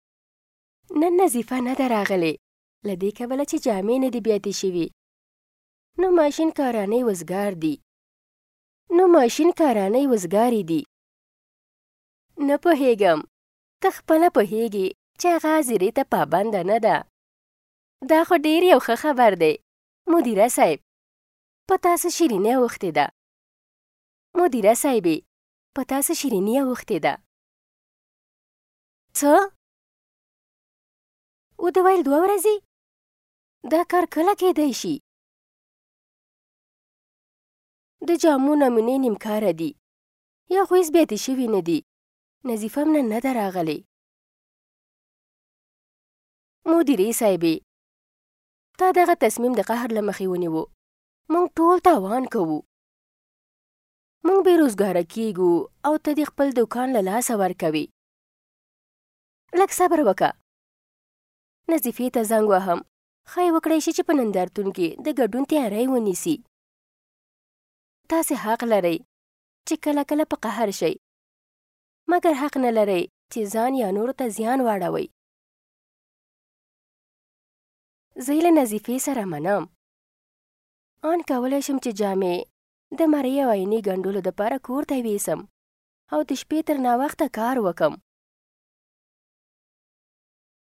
Professional Female Pashto Voice Samples
Our female Pashto voice artists offer a wide range of tones.
FEMALE_PASHTO-2.mp3